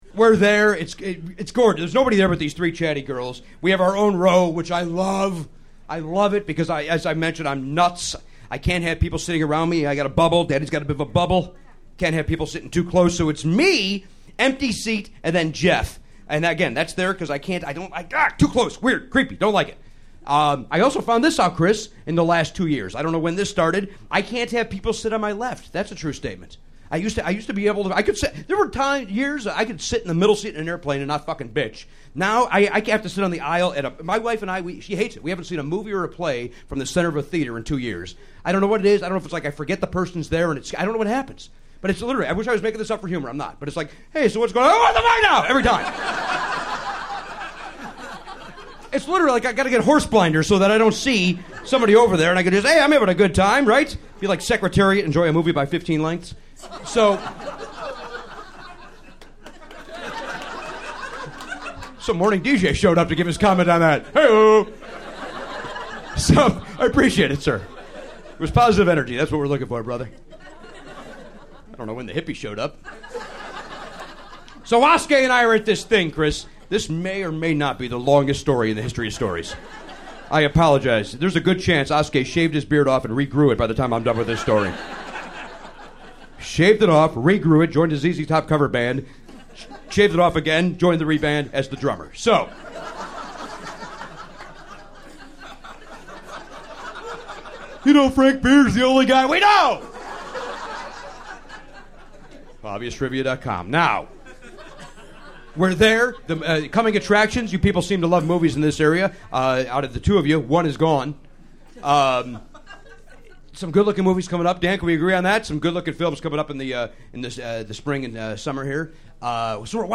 Jimmy Pardo’s one of my favorite standup comics in the world – no one’s more in the moment on stage than Jimmy.